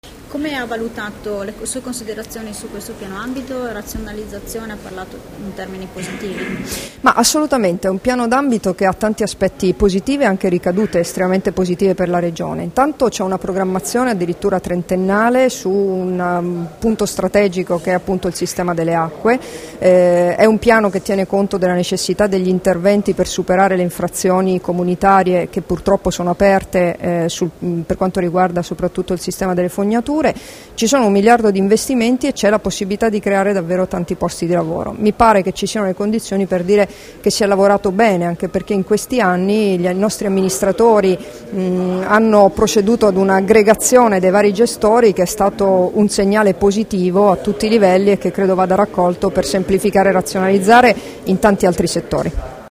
Dichiarazioni di Debora Serracchiani (Formato MP3) [844KB]
rilasciate a margine della presentazione del Piano d'ambito del CATO Centrale Friuli, a Udine il 17 giugno 2014